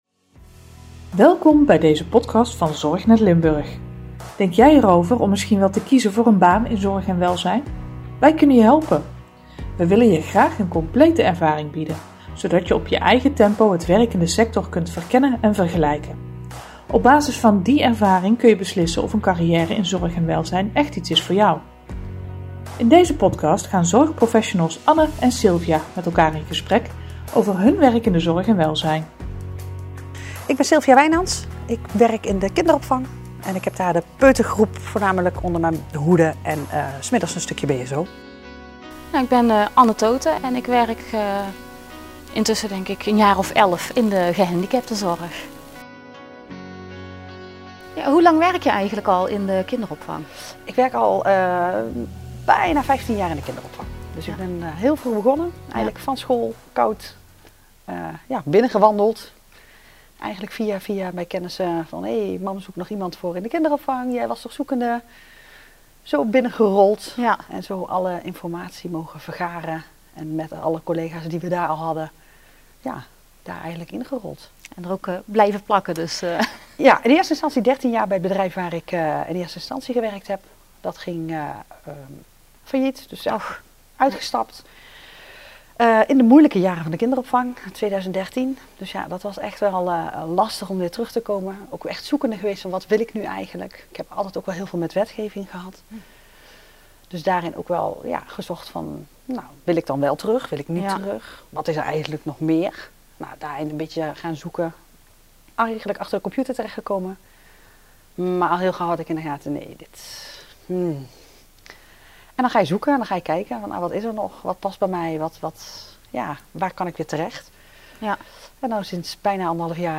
In een reeks video's gaan tien zorgprofessionals gingen met elkaar in gesprek over de overeenkomsten én verschillen in hun beroep. De grote overeenkomst? Hun passie voor de zorg!